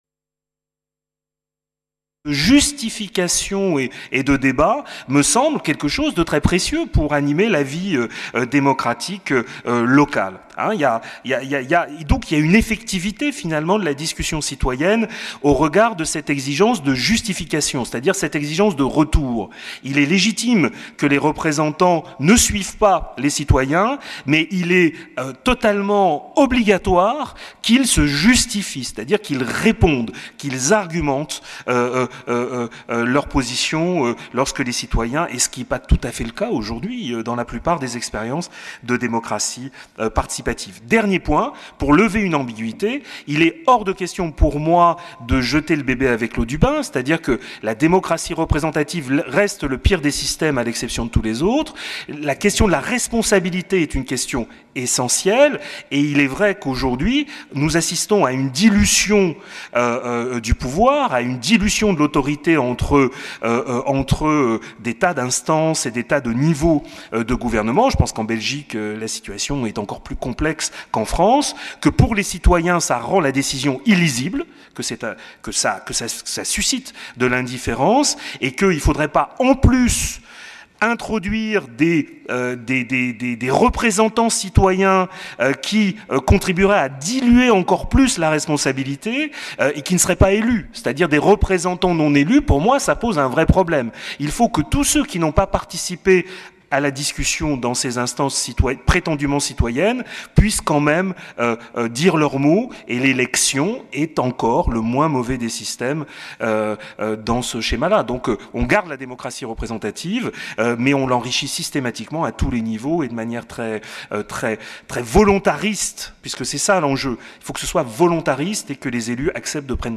5. SP_ débat_audio.mp3 — 32.4 MB